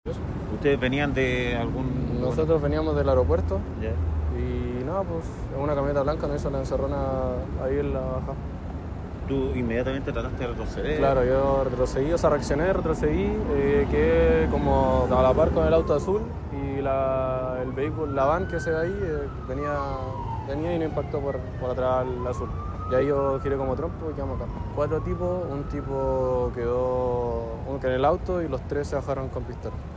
Por su parte, quien manejaba el furgón relató a Radio Bío Bío cómo se produjo el impacto.